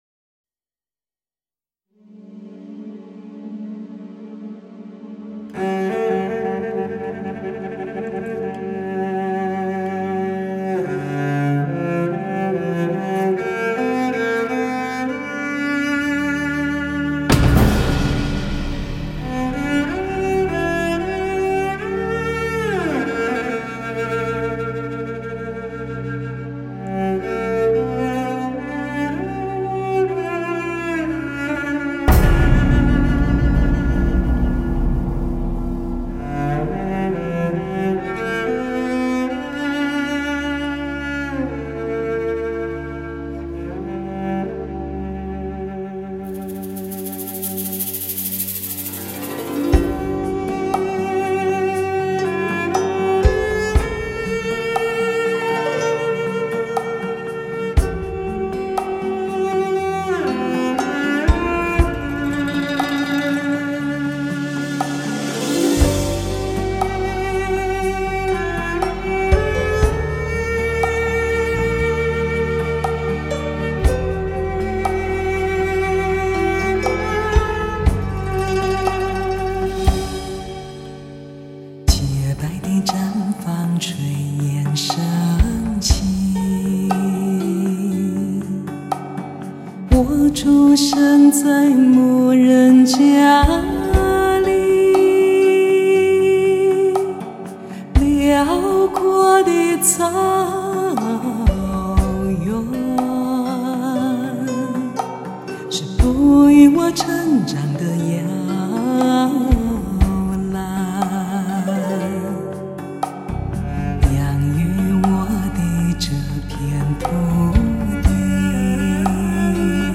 十七首来自草原充满浓郁民族风情的蒙古歌曲
通过原生态录音发烧技术营造出人间天堂--蒙古草原的迷人美景